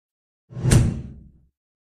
Stamp Impact Sound Effect.mp3